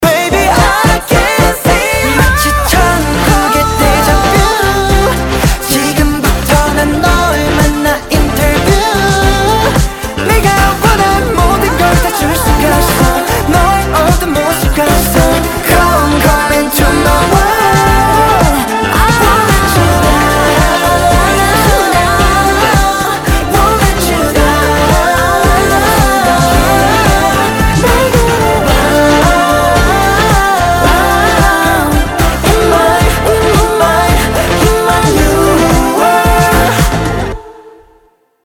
• Качество: 320, Stereo
позитивные